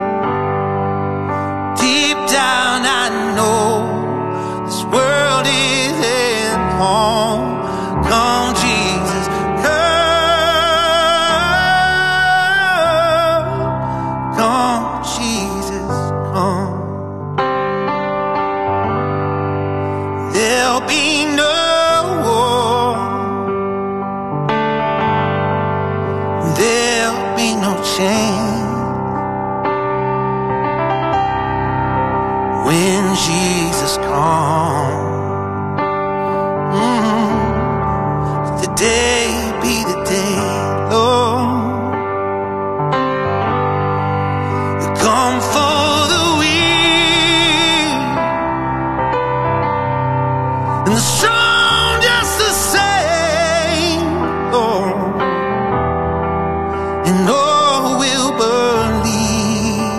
gospel
worship song, piano